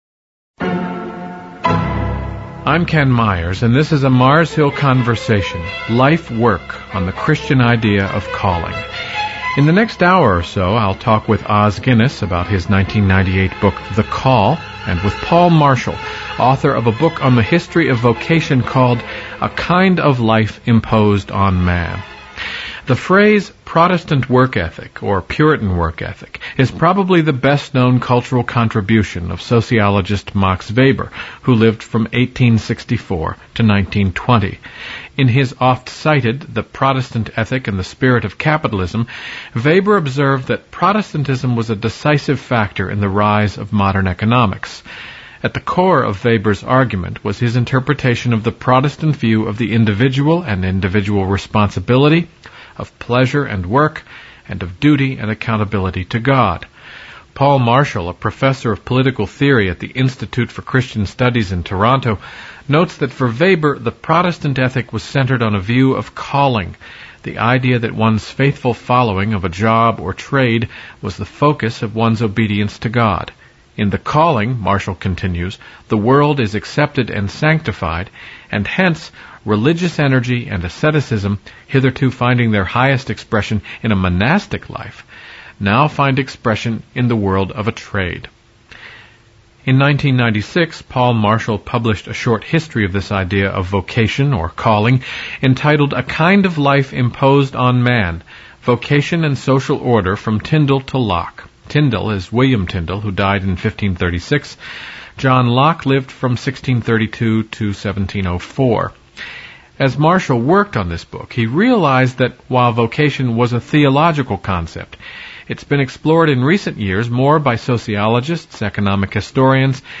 MARS HILL AUDIO Conversations